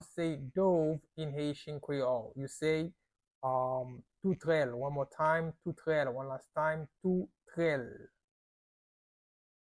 Listen to and watch “Toutrèl” audio pronunciation in Haitian Creole by a native Haitian  in the video below:
Dove-in-Haitian-Creole-Toutrel-pronunciation-by-a-Haitian-teacher.mp3